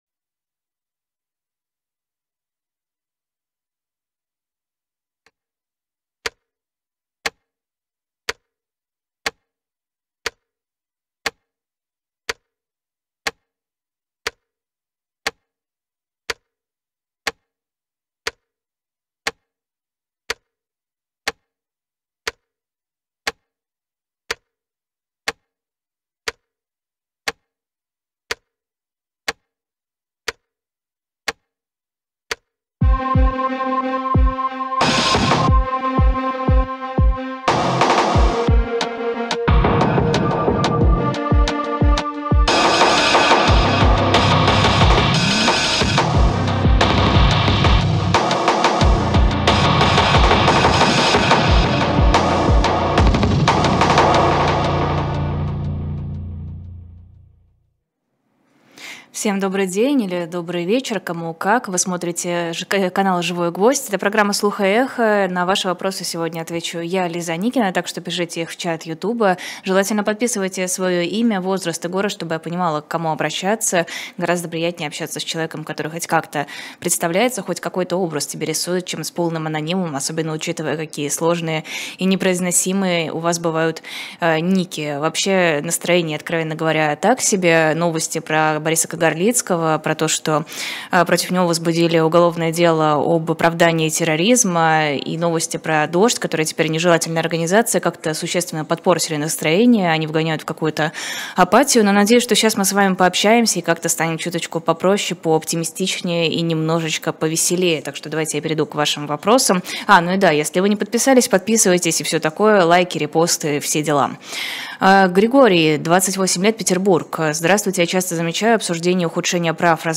На ваши вопросы в прямом эфире